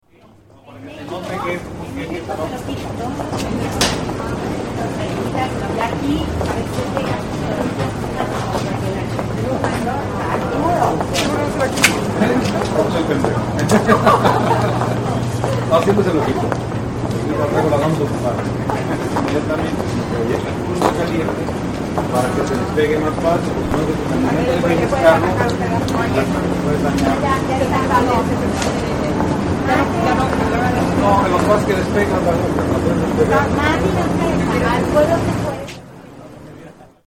NYC marathon
Tags: New York New York city New York city sounds NYC Travel